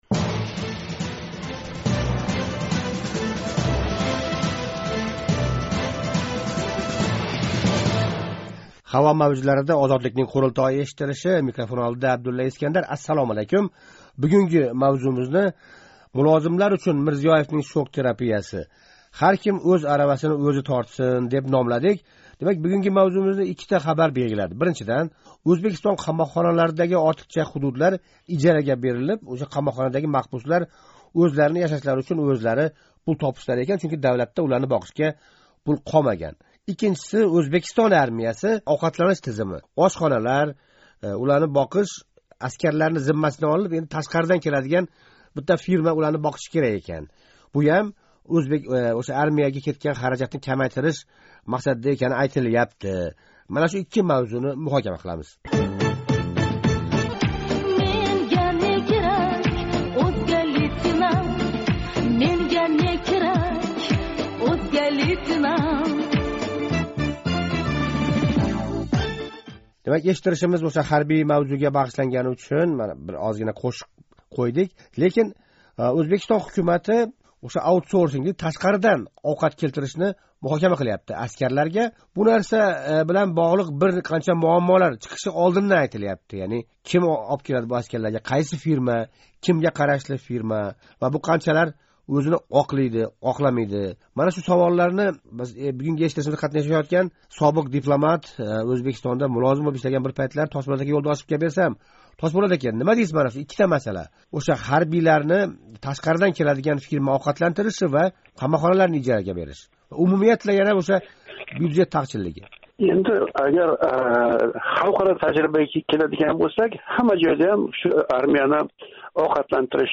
Ўзбекистон бюджети ўз камарларини сиқиб боғлаб¸ парҳезга ўтираяптими¸ деган савол жавобини "Қурултой" эшиттиришида муҳокама қилдик.